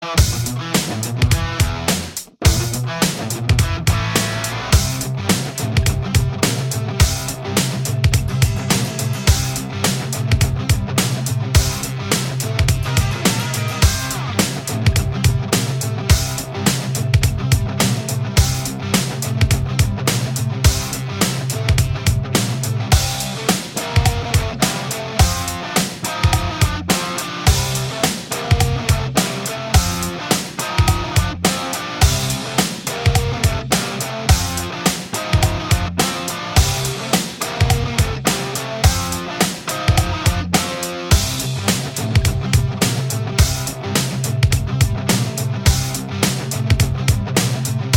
Minus All Guitars Rock 5:10 Buy £1.50